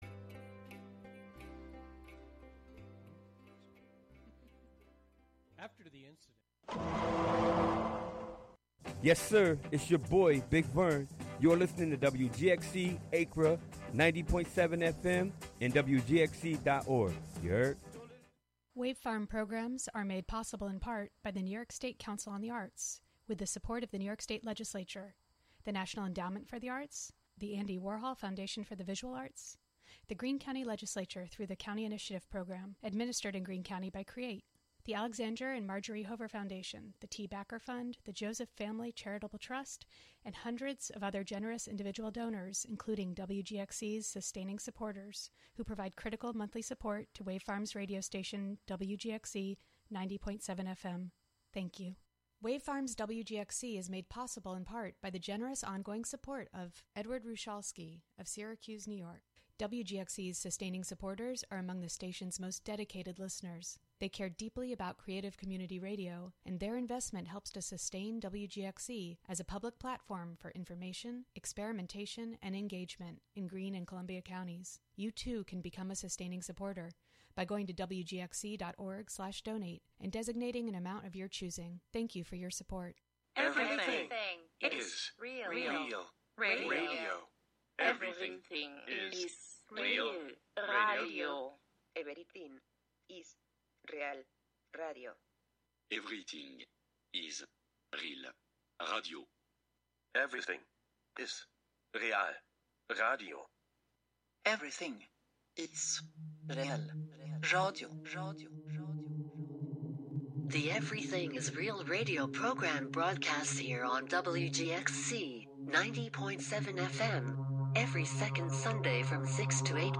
Honoring the central importance of music and sound in decolonization practices. Amplifying Indigenous worldviews, knowledge systems, and sound practices.